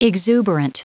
Prononciation du mot exuberant en anglais (fichier audio)
Prononciation du mot : exuberant